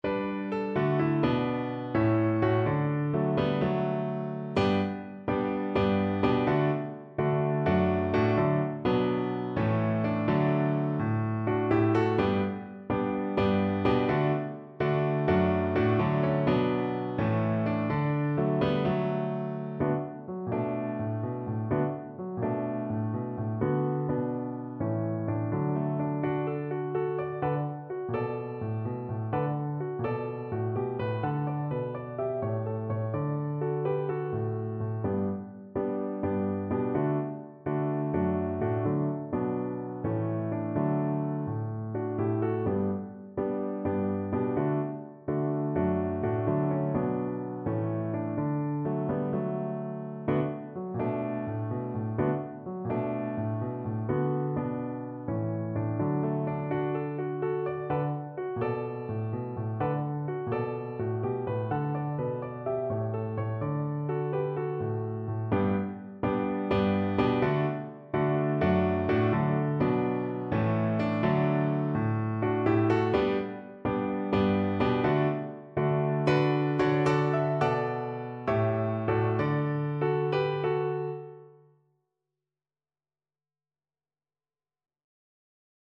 4/4 (View more 4/4 Music)
Allegro = 126 (View more music marked Allegro)